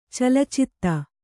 ♪ calacitta